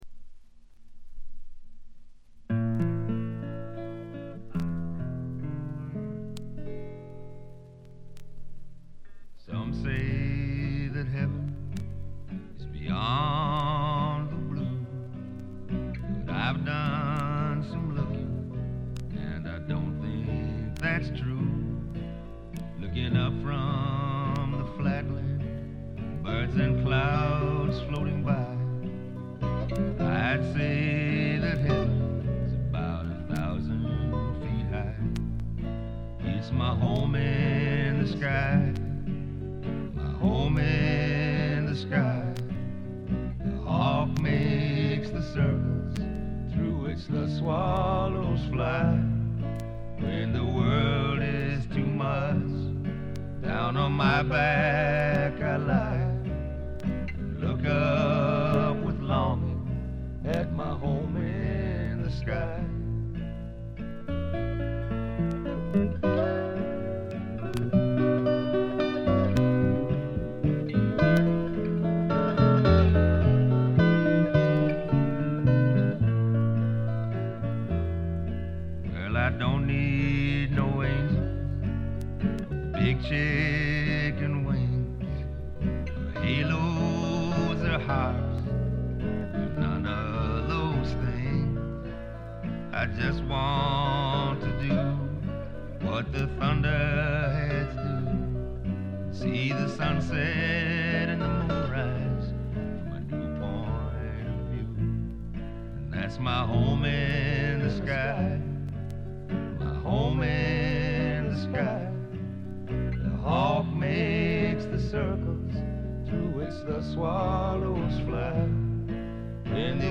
B2で軽いチリプチ。
にがみばしった男の哀愁を漂わせたヴォーカルがまず二重丸。
試聴曲は現品からの取り込み音源です。